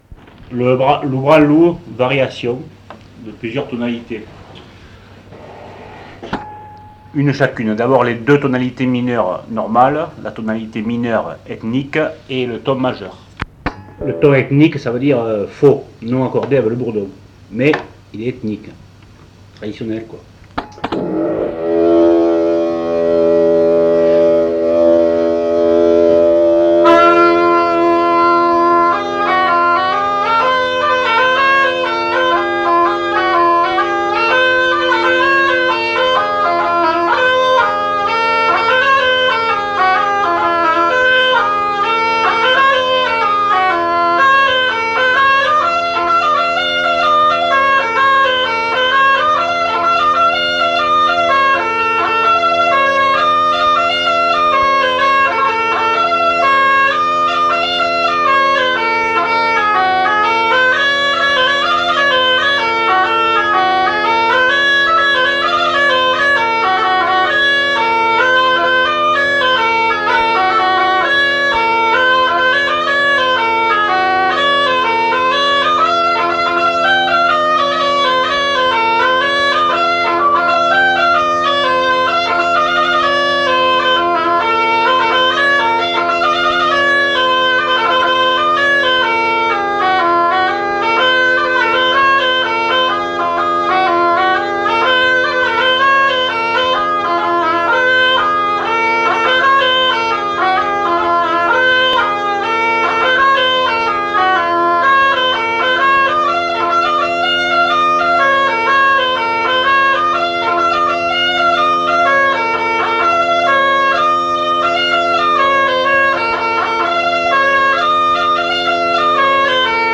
Aire culturelle : Cabardès
Genre : morceau instrumental
Instrument de musique : craba